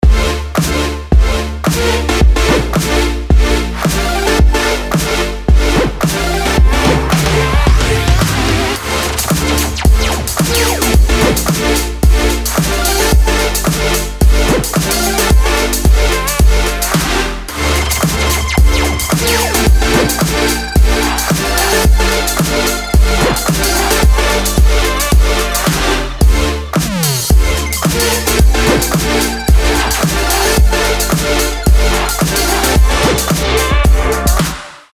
• Качество: 320, Stereo
8-бит